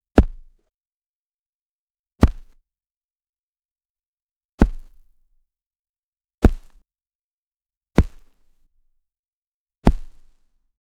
soft-impact-of-a-ball-landing-on-grass-m3lx6bui.wav